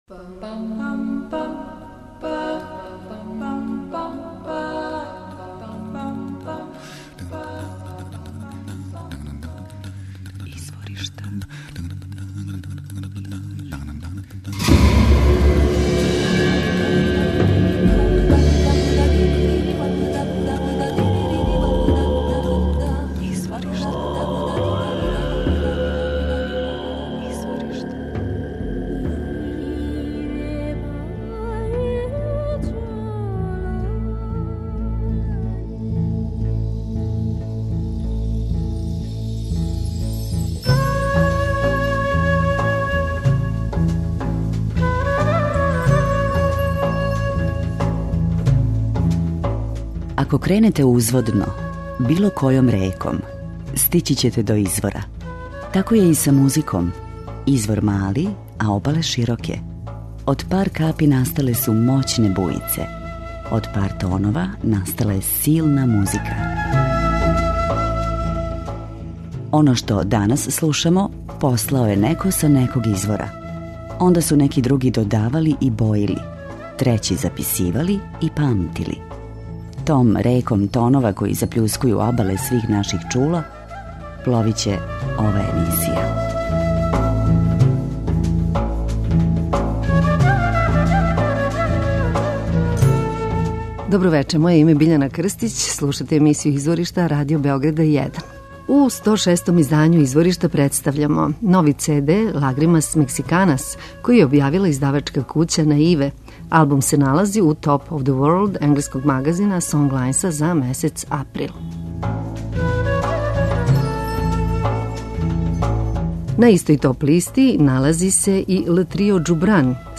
акустична и електрична гитара
Цео албум испуњен је дивном енергијом, љубављу и оптимизмом.